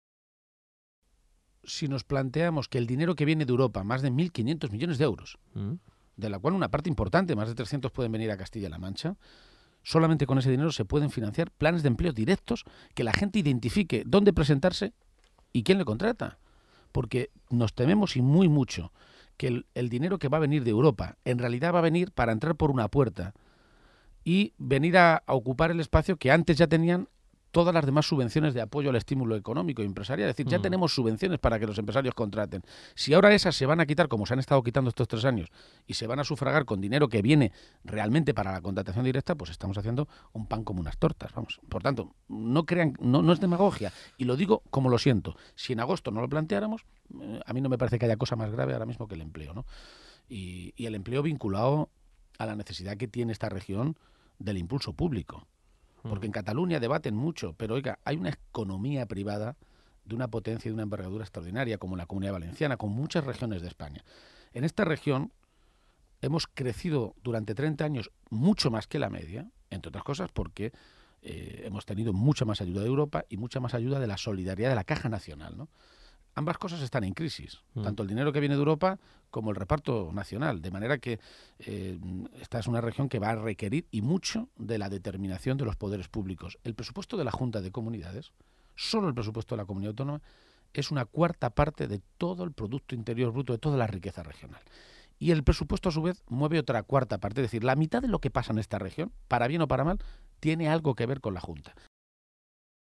Cortes de audio de la rueda de prensa
Audio Page-entrevista OCR 1